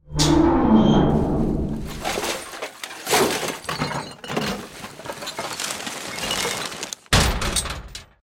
dumpster_1.ogg